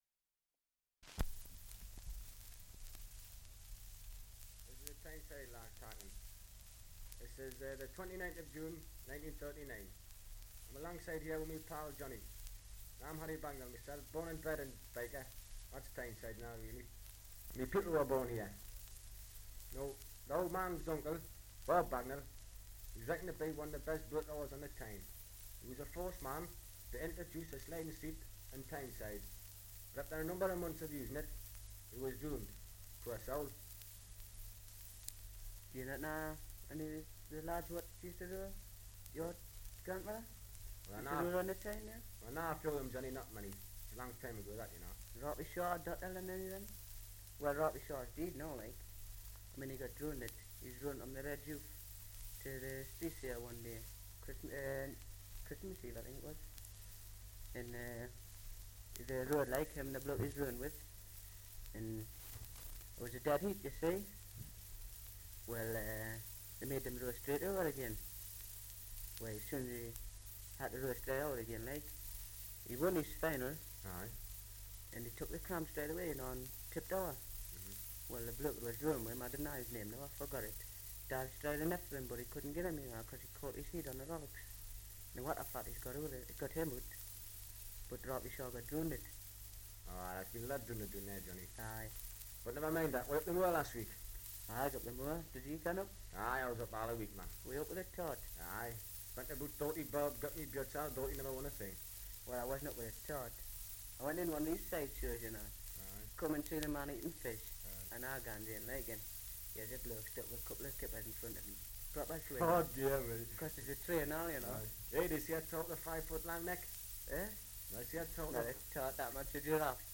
2 - Dialect recording in Newcastle upon Tyne
78 r.p.m., cellulose nitrate on aluminium